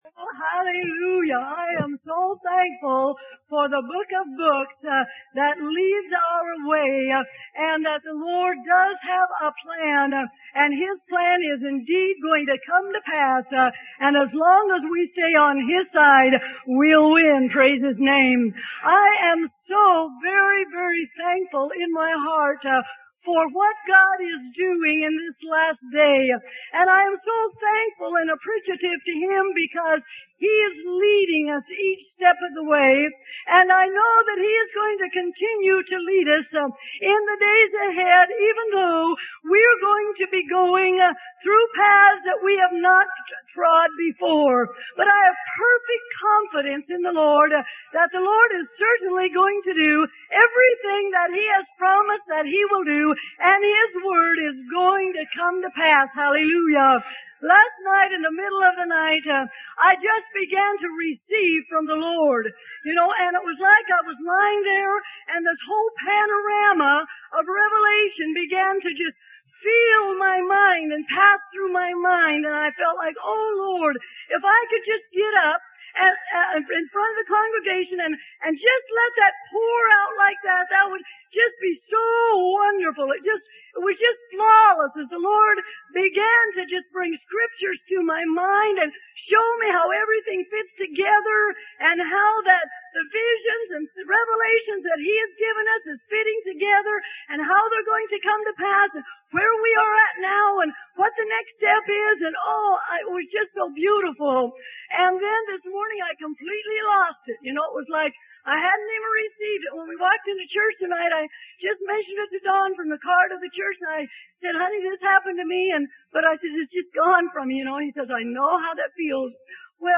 Sermon: God's Call to His Children - Who Will Hear?